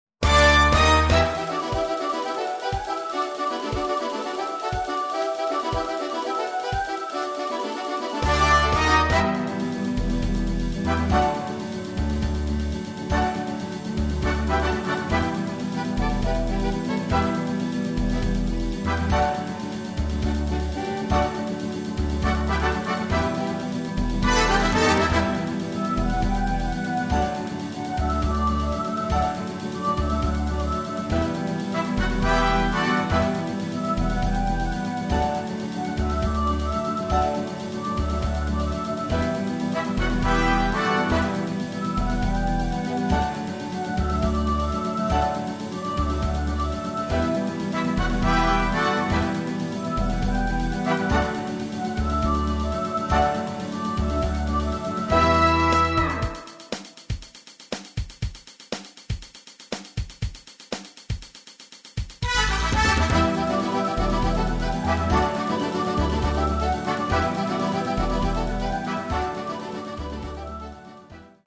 Gattung: Big Band mit Gesang
Besetzung: Big-Band-Noten
Tonart: E-Moll
Tonhöhe: Trompete bis c3
Tanz-Info: Salsa | Samba